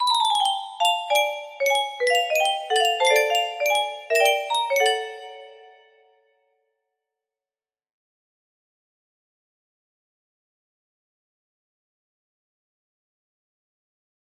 Simple_3 music box melody